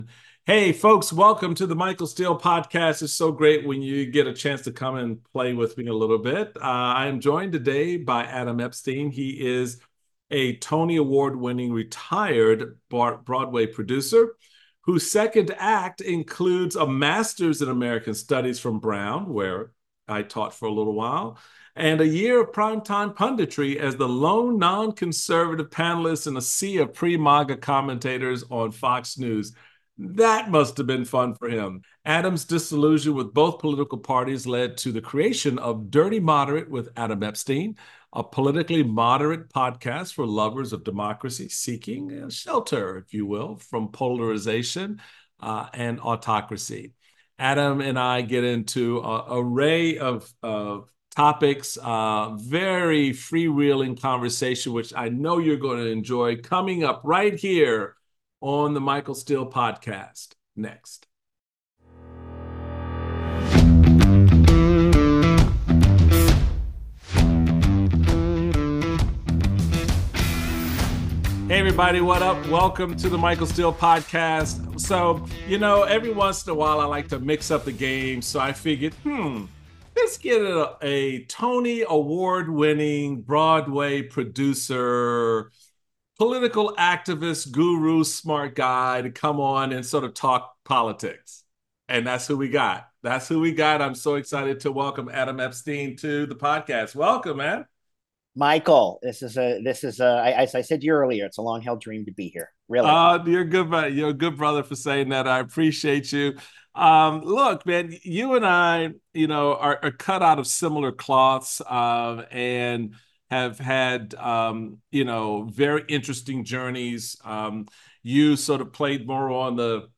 Michael Steele speaks with Tony Award-winning retired Broadway producer turned moderate podcast host, Adam Epstein. The pair discuss the upcoming presidential election, polarization in politics and the need for a more moderate approach.